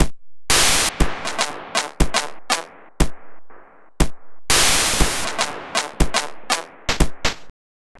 120_BPM
ChipShop_120_Drums_05.wav